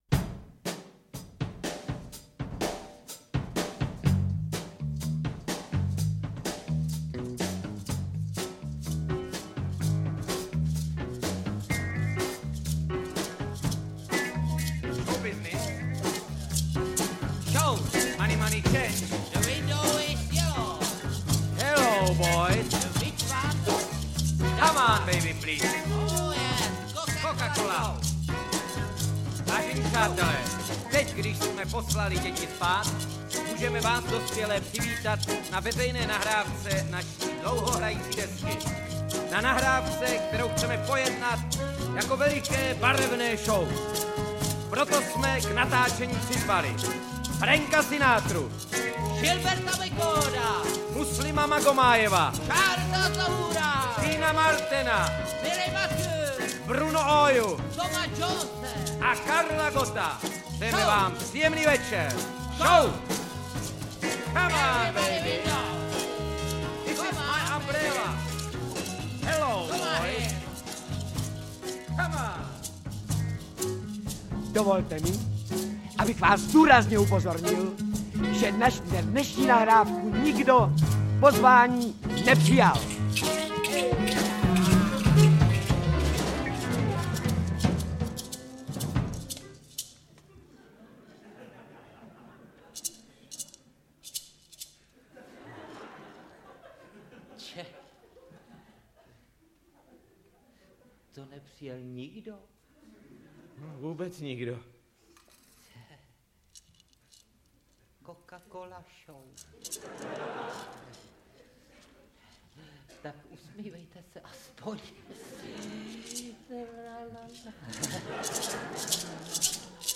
Interpreti:  Petr Nárožný, Luděk Sobota, Miloslav Šimek
Skeče a scénky komediální dvojice Luďka Soboty a Miloslava Šimka. Kromě autorů účinkuje i Petr Nárožný.